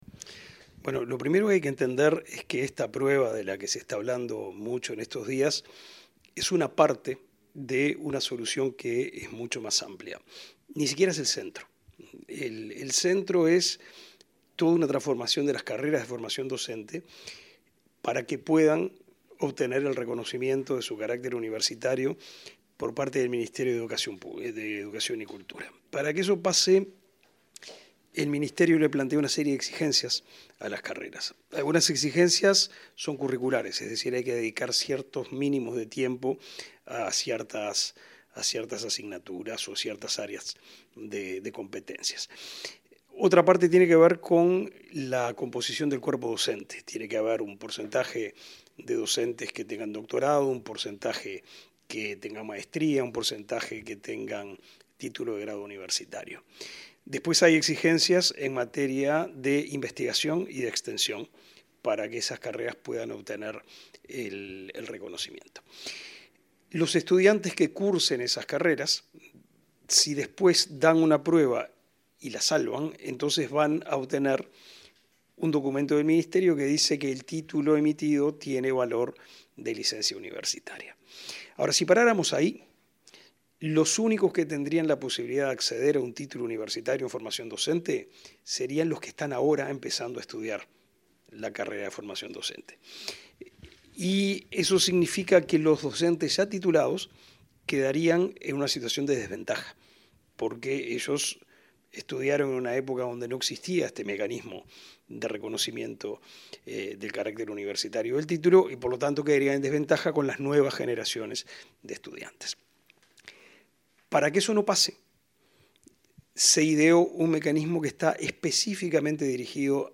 Entrevista al ministro de Educación y Cultura, Pablo da Silveira